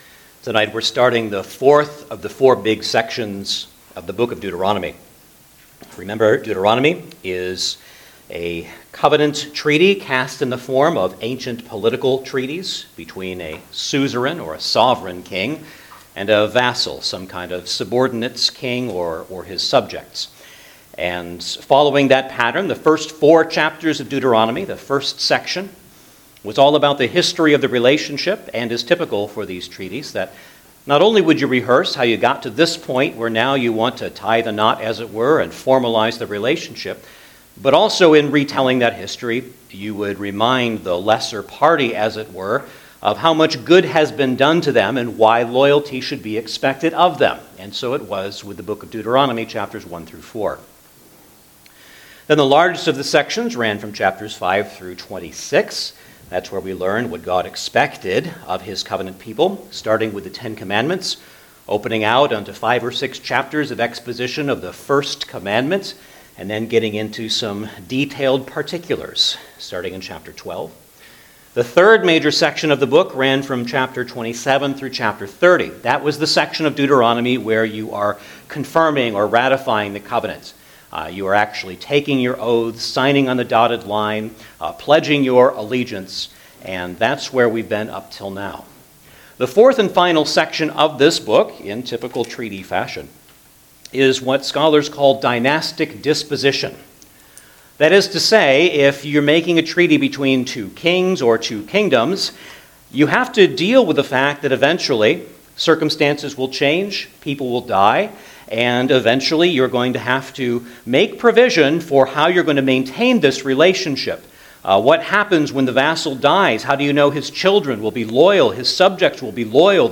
Deuteronomy Passage: Deuteronomy 31:1-29 Service Type: Sunday Evening Service Download the order of worship here .